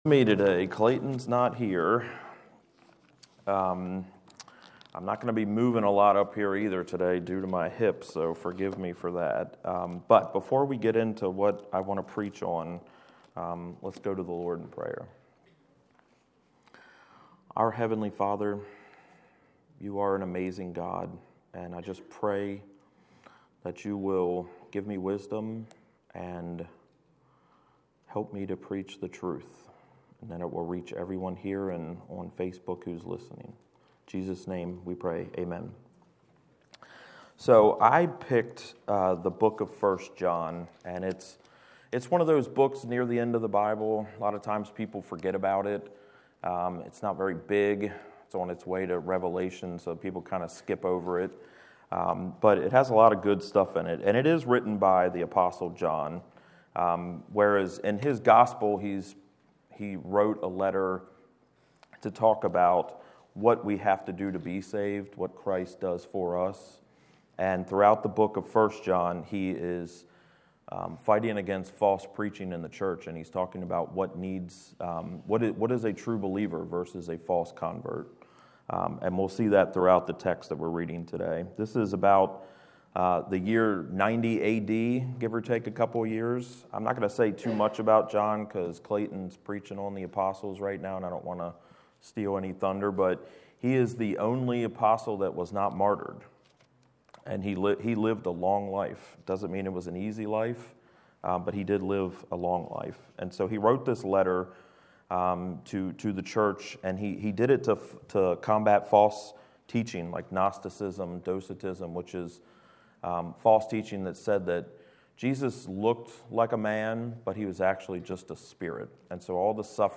Series: Guest Speaker
Service Type: Sunday Worship Service